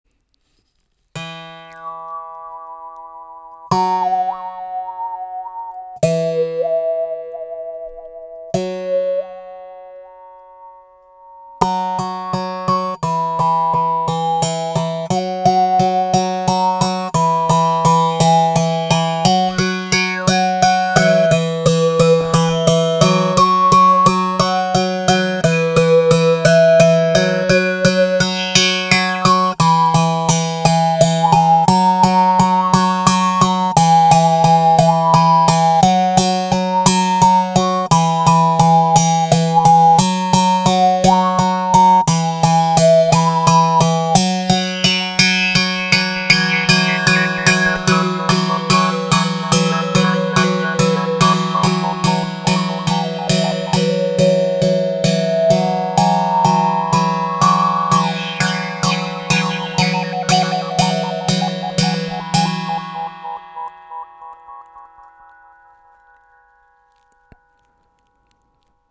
ARC EN BOUCHE 2 CORDES avec cuillère harmonique
Les sons proposés ici sont réalisés sans effet.
La baguette sera utilisée pour percuter les cordes, l’une, l’autre ou Les 2.